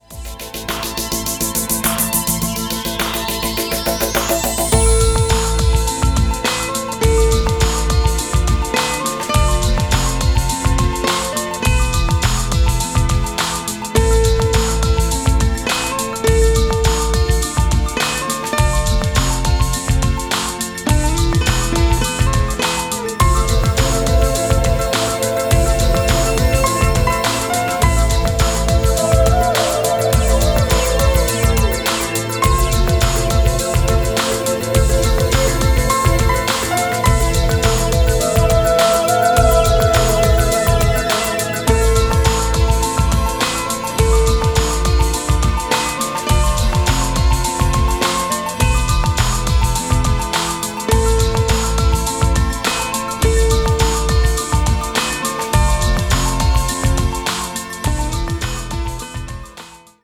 • Качество: 256, Stereo
красивые
спокойные
инструментальные